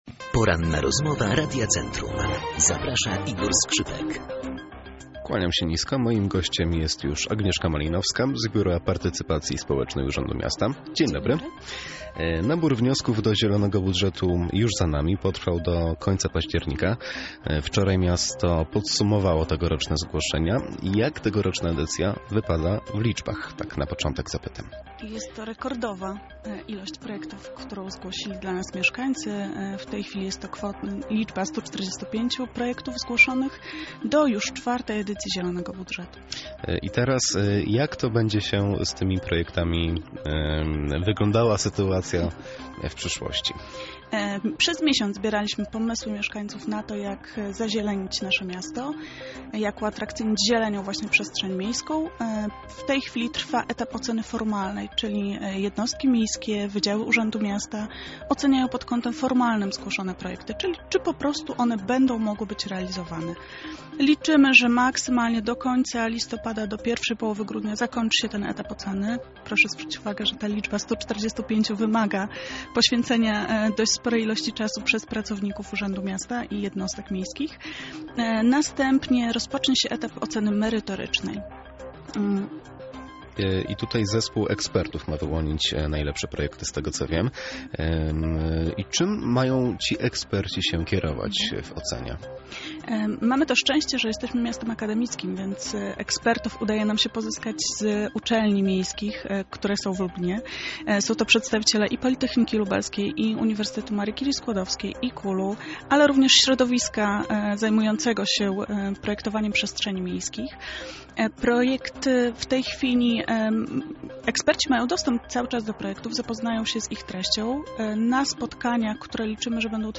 Pełna rozmowa na temat Zielonego Budżetu dostępna poniżej: